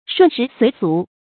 順時隨俗 注音： ㄕㄨㄣˋ ㄕㄧˊ ㄙㄨㄟˊ ㄙㄨˊ 讀音讀法： 意思解釋： 順應時勢，隨從習俗。